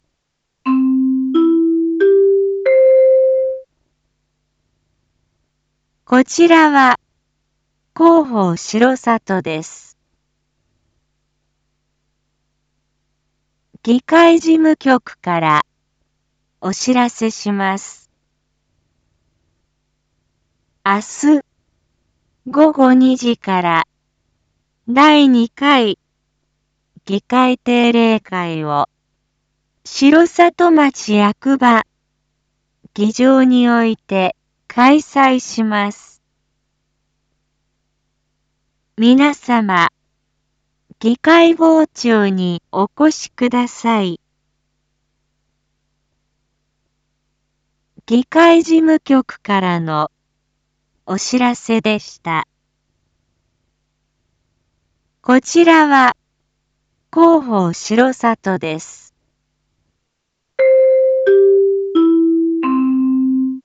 BO-SAI navi Back Home 一般放送情報 音声放送 再生 一般放送情報 登録日時：2023-06-12 19:01:05 タイトル：6/12夜 議会 インフォメーション：こちらは広報しろさとです。